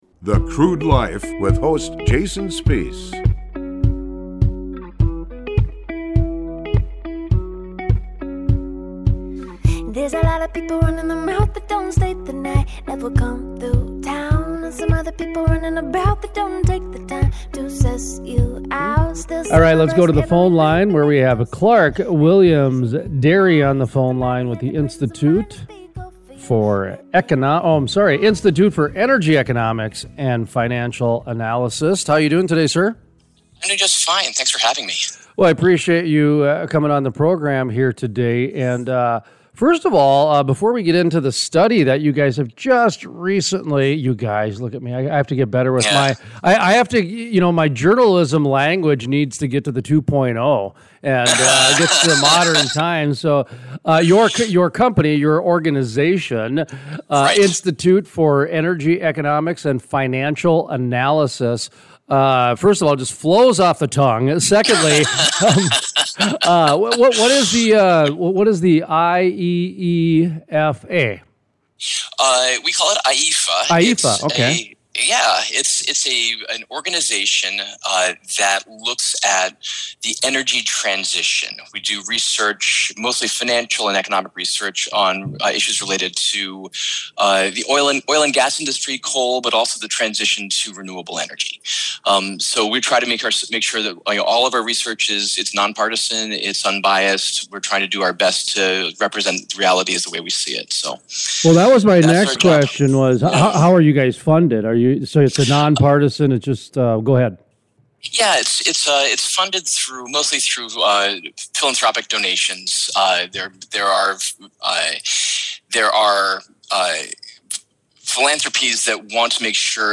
Here are this week’s special guests on The Crude Life Week in Review.